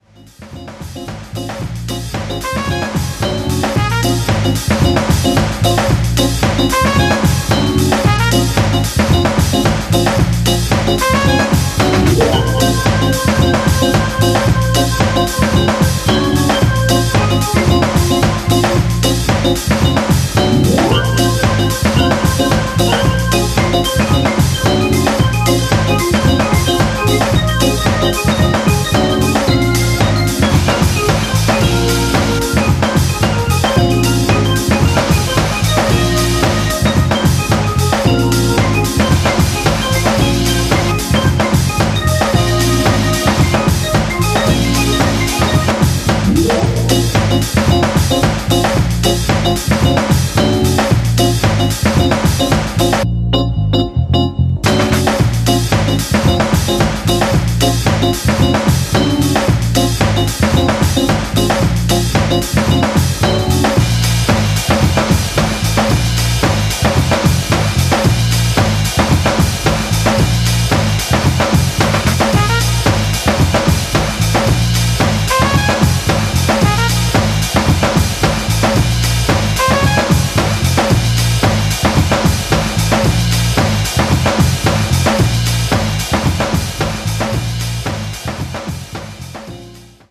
ジャンル(スタイル) JAZZ / HOUSE / DOWNTEMPO / CLASSIC / DEEP HOUSE